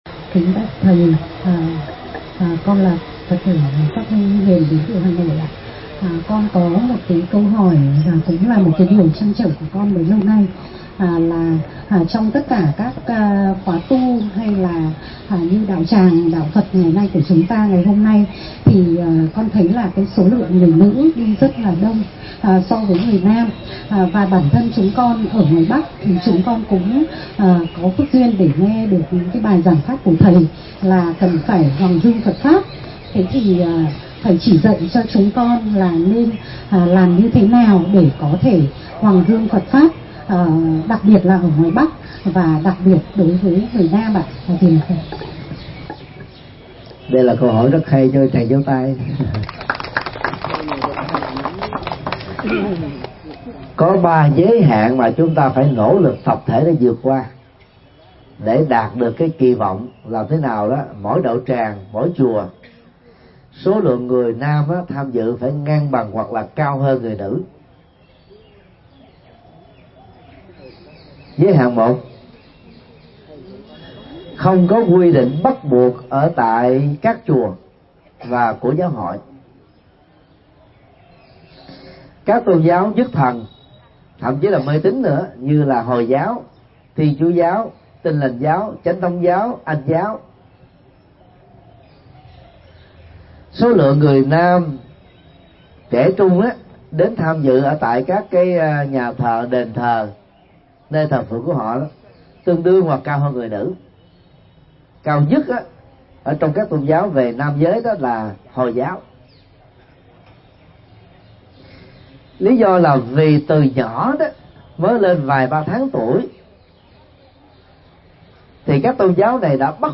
Vấn đáp: Hướng dẫn độ người thân theo Phật Pháp